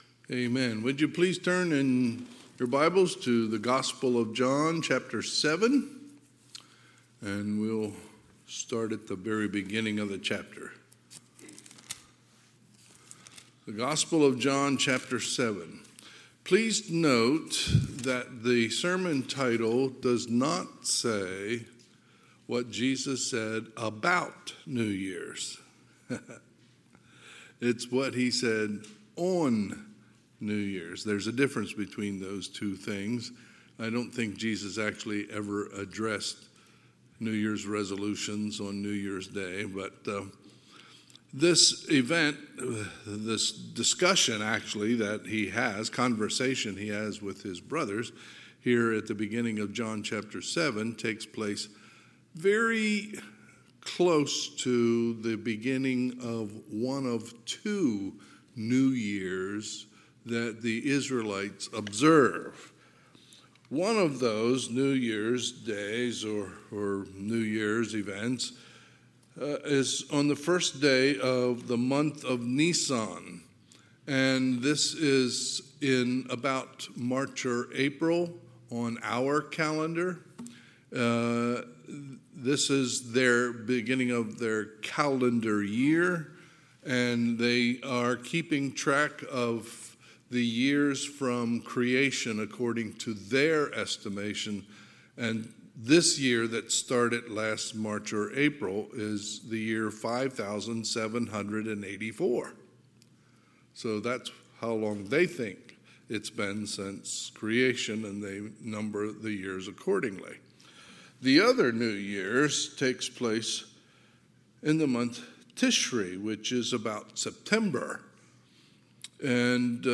Sunday, December 31, 2023 – Sunday PM